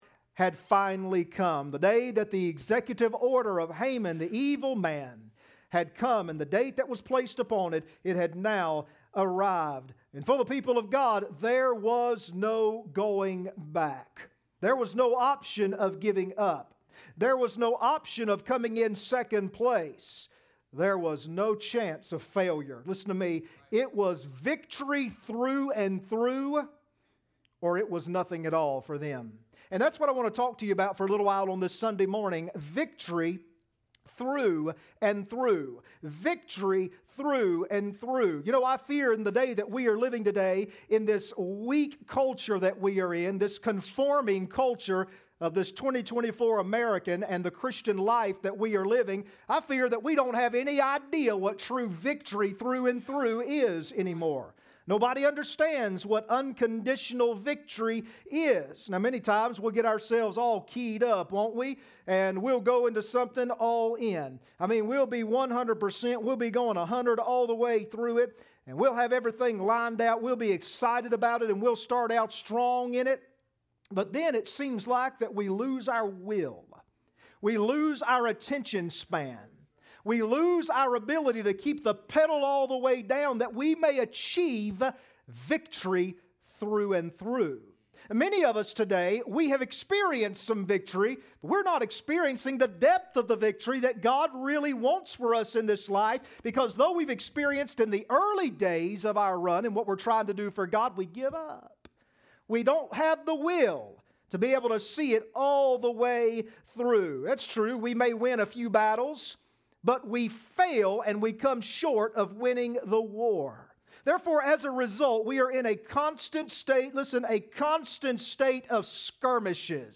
Esther 9:1-2 Service Type: Sunday Morning Next Sermon